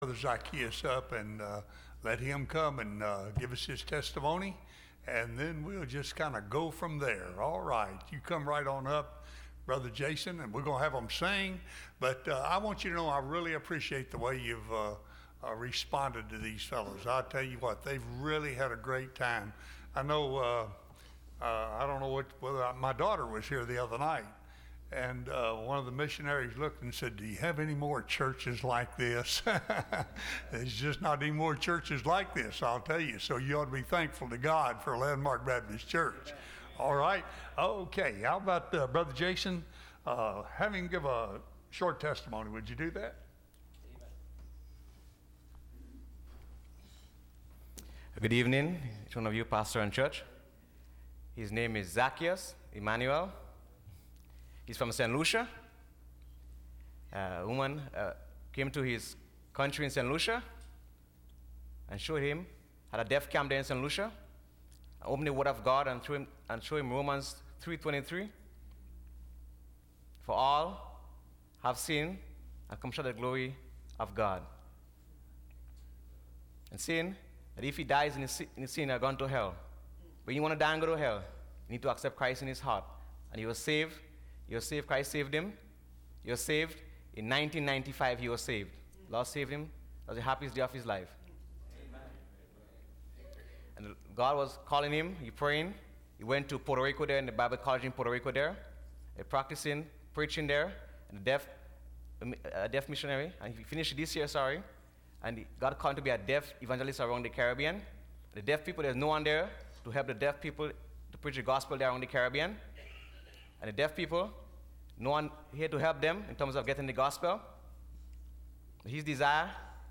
Testimonies – Landmark Baptist Church
Service Type: Missions Conference